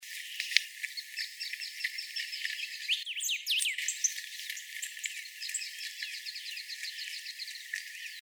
Sulphur-bearded Reedhaunter (Limnoctites sulphuriferus)
Primero se escucha al curutié ocráceo, luego a un pepitero de collar y otra ave, y al final de nuevo al curutié ocráceo.
Location or protected area: Reserva Ecológica Costanera Sur (RECS)
Condition: Wild
Certainty: Recorded vocal